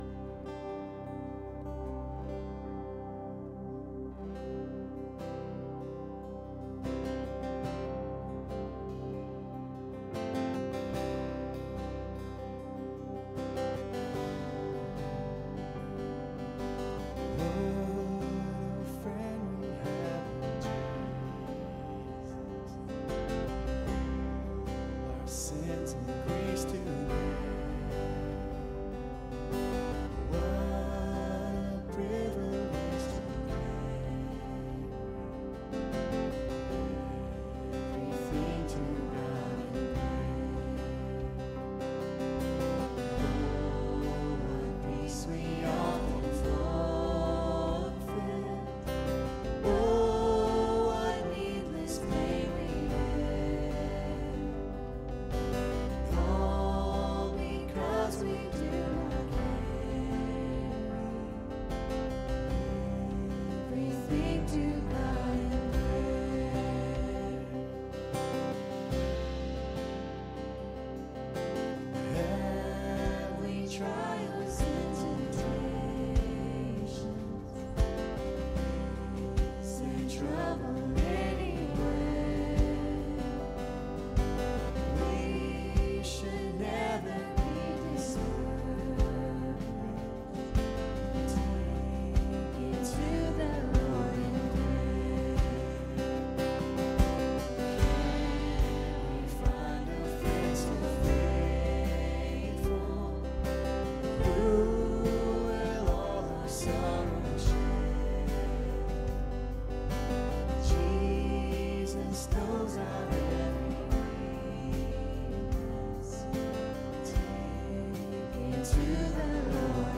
Sunday Morning Sermon on Matthew 7:12.